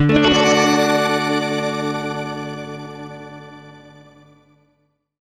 GUITARFX 1-L.wav